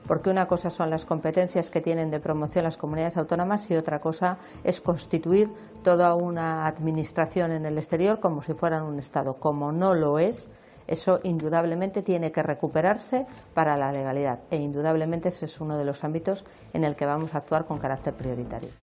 La vicepresidenta del Gobierno, Soraya Sáenz de Santamaría, ha puntualizado en una entrevista a Telecinco que el Gobierno catalán tiene ya "poco tiempo” para ir al Senado y hacer las alegaciones.